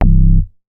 MoogRCA 002.WAV